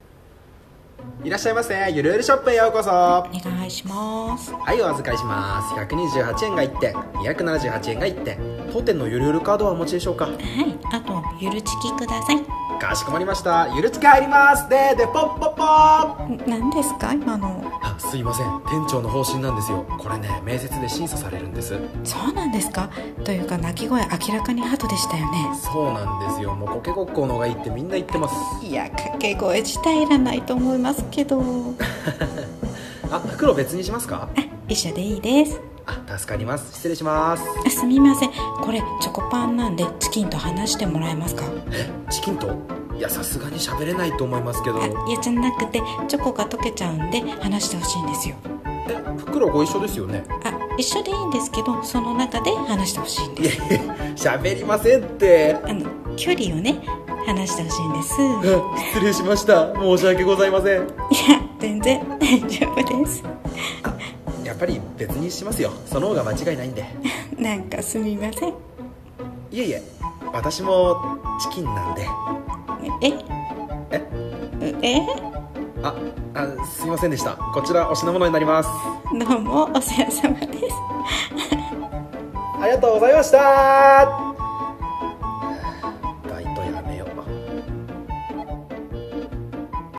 緩声劇「チョコパンとチキンを離してくれない店員」